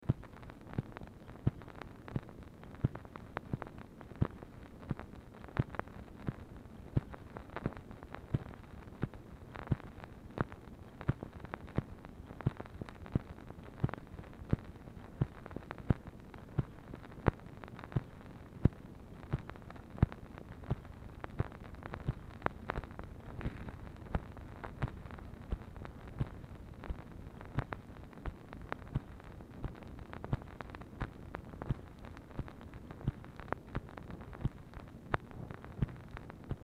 MACHINE NOISE
BLANK NON-GROOVED SEGMENT ON ORIGINAL DICTABELT
Format Dictation belt